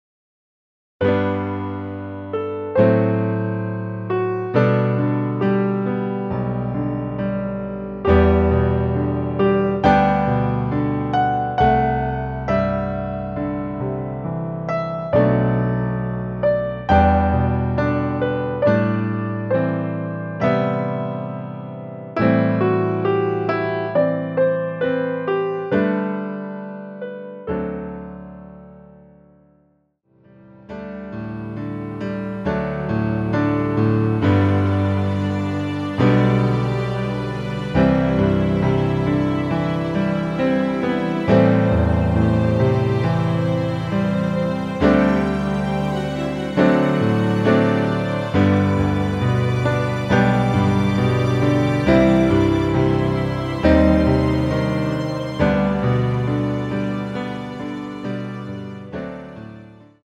-5)이며 피아노와 스트링만으로 편곡한 MR 입니다.
앞부분30초, 뒷부분30초씩 편집해서 올려 드리고 있습니다.
중간에 음이 끈어지고 다시 나오는 이유는